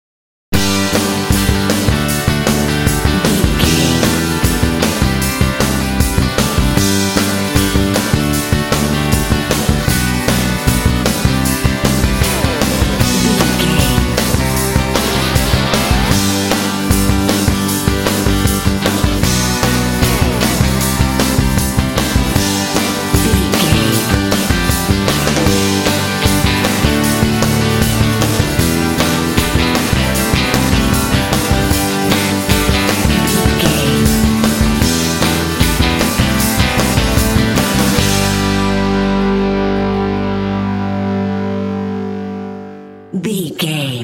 Epic / Action
Uplifting
Ionian/Major
bouncy
happy
groovy
bright
motivational
electric guitar
bass guitar
drums
rock
alternative rock
indie